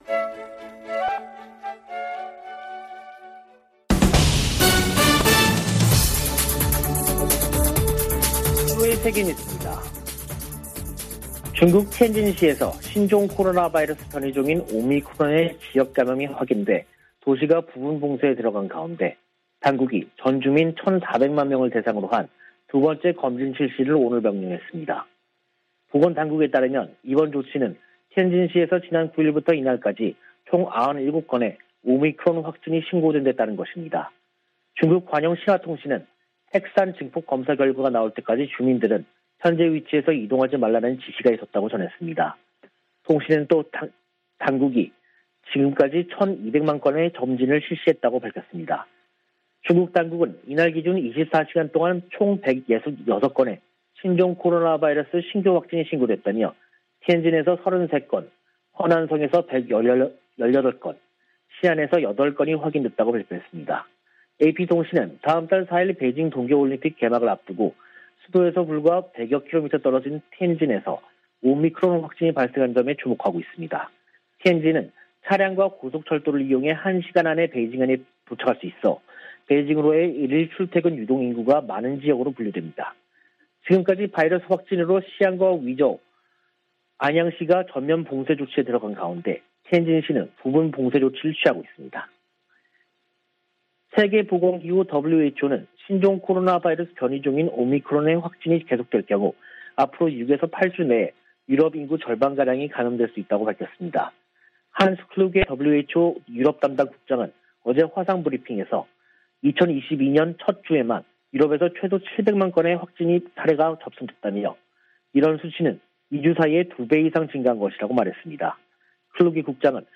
VOA 한국어 간판 뉴스 프로그램 '뉴스 투데이', 2022년 1월 12일 3부 방송입니다. 북한은 11일 쏜 발사체가 극초음속 미사일이었고 최종 시험에 성공했다고 발표했습니다. 백악관이 북한의 최근 미사일 발사를 규탄하면서 추가 도발 자제와 대화를 촉구했습니다. 유엔 사무총장은 북한의 연이은 미사일을 발사를 매우 우려하고 있다고 밝혔습니다.